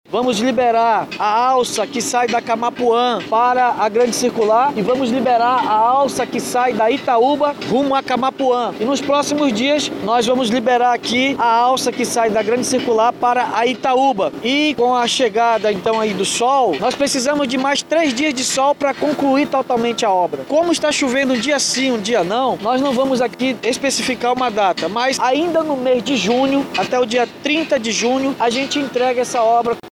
Em uma entrevista coletiva, concedida à imprensa, o Chefe do Executivo Municipal disse que a instabilidade climática atrasou o cronograma de conclusão da obra.